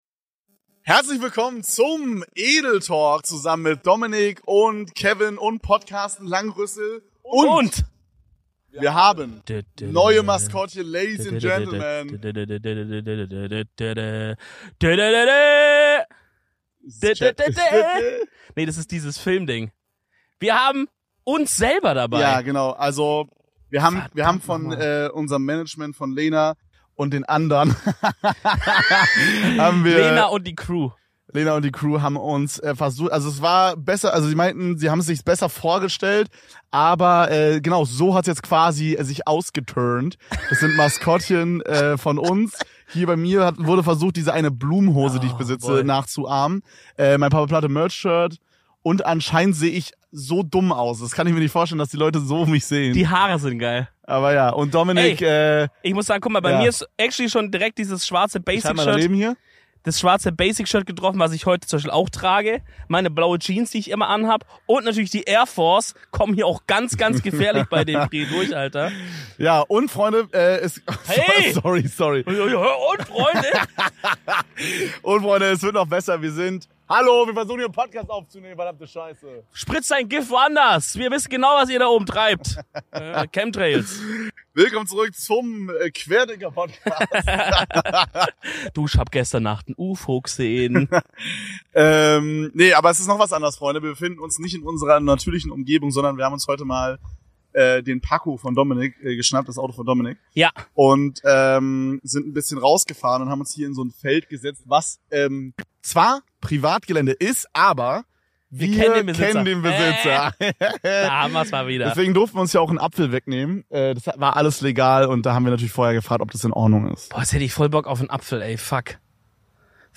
Edeltalk goes outdoor. Heute befinden wir uns auf den schönsten Wiesen und Feldern Kölns, natürlich inklusive unserer Podcast Maskottchen Podcarsten und Sir Langrüssel.